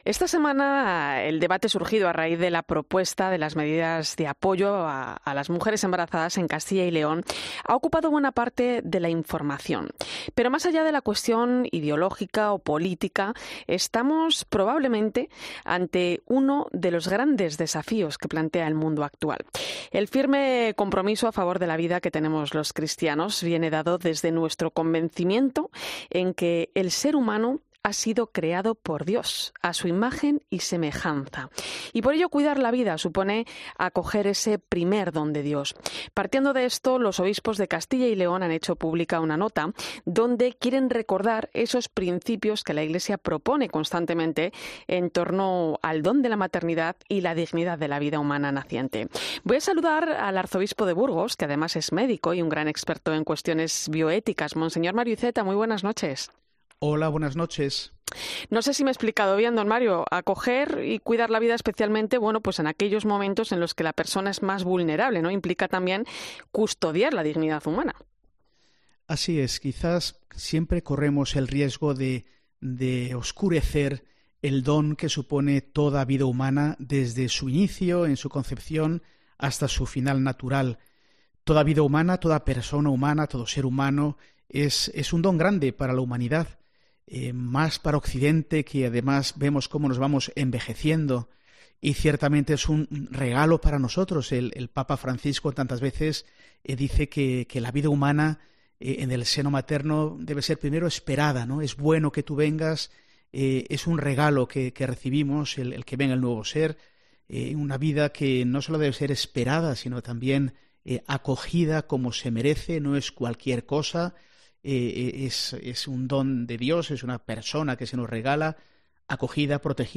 El arzobispo de Burgos se pronuncia en 'La Linterna de la Iglesia' sobre la nota de los obispos de Castilla y León acerca del debate sobre el aborto